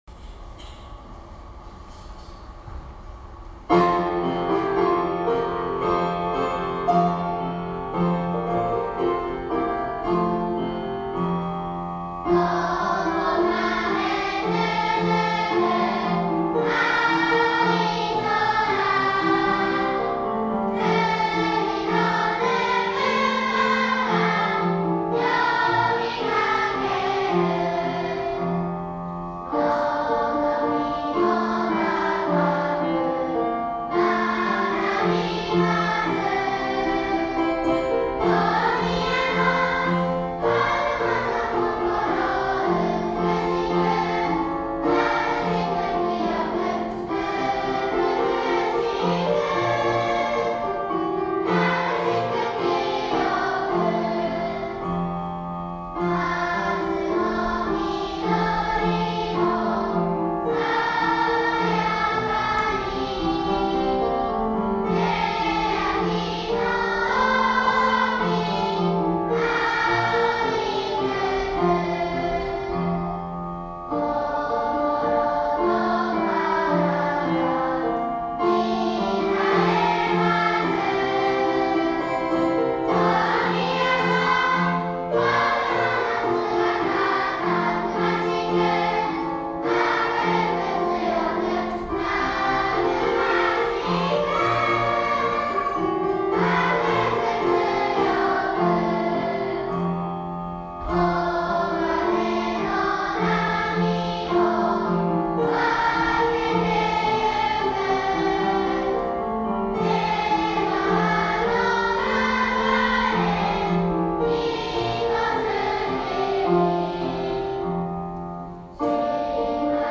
龍ケ崎市立大宮小学校
校歌斉唱（歌入り）